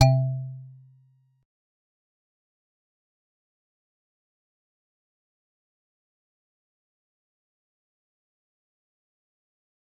G_Musicbox-C3-pp.wav